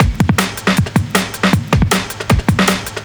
drums06.wav